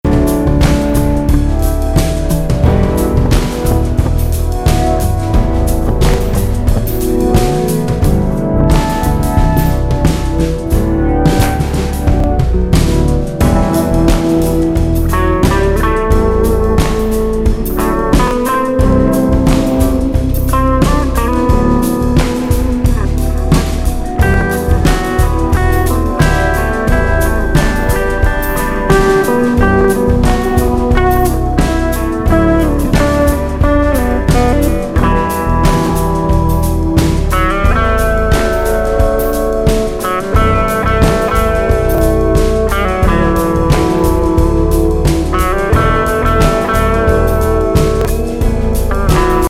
有機的な生音の心地良さと打ち込みの程良いキックが融合した好ELECTRIC
ROCKインストもの!包み込まれるようなレンジの広がりを見せる上音と、
生ドラムのグルーヴが最高の一枚!ジャケも良い◎